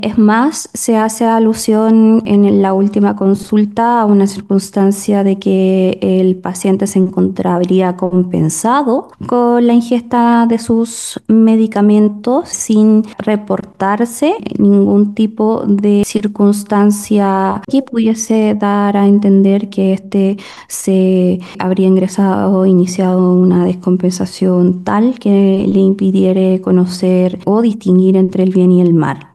Eso último no fue acogido por la jueza del Tribunal de Letras y Garantía de Calbuco, Marianela Mancilla, quien con la revisión de documentos médicos razonó que el hombre no tendría dificultades para distinguir entre el bien y el mal.